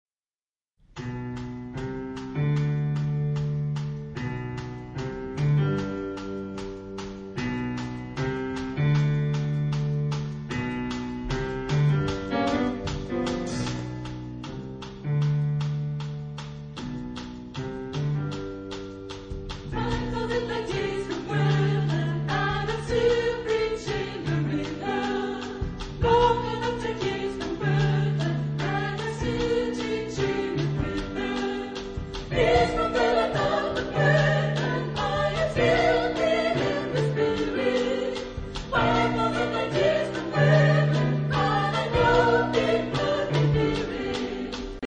Type of Choir: SATB  (4 mixed voices )
Discographic ref. : 7. Deutscher Chorwettbewerb 2006 Kiel